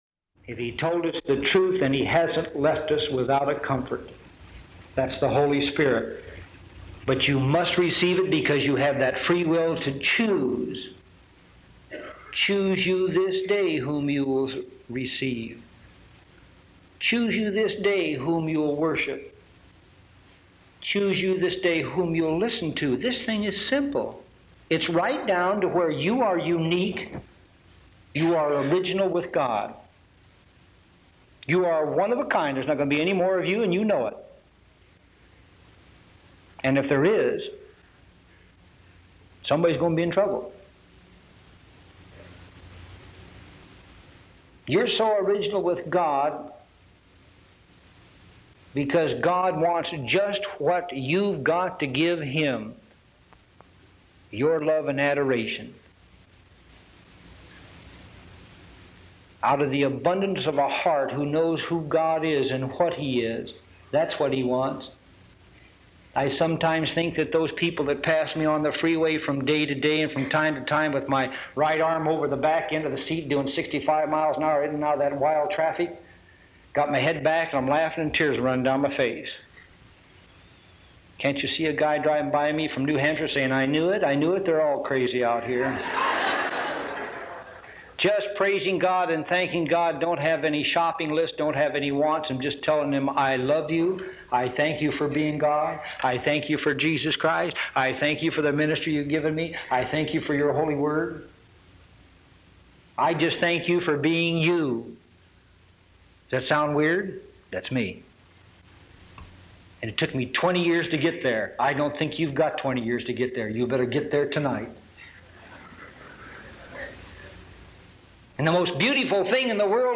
All so Simple download sermon mp3 download sermon notes Welcome to Calvary Chapel Knoxville!